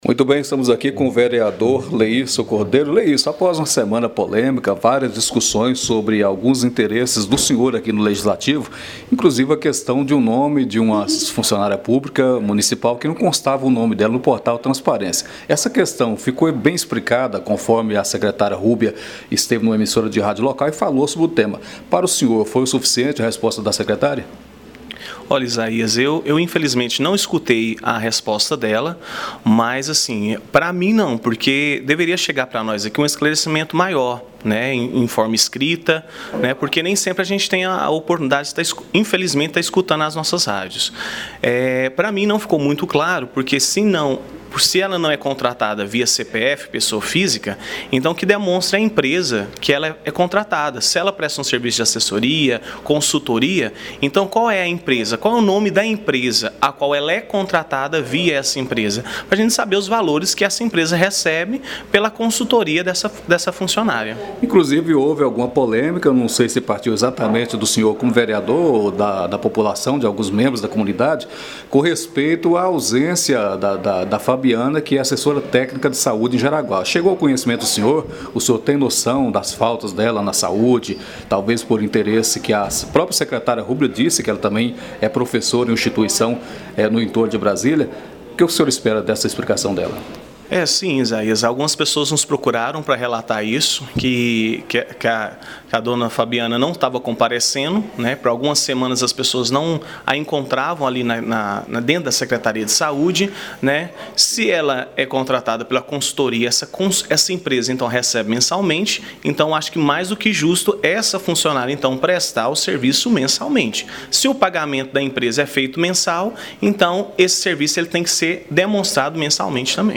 Em entrevista, o vereador Leirso Cordeiro disse que a resposta da secretária não foi o que ele esperava, ou seja, ele aguarda uma explicação formal, podendo ser feito por ofício, inclusive apresentação de valores contratuais entre a Secretaria Municipal de Saúde com a empresa de assistência técnica, independente de ter sido por meio de licitação ou contrato.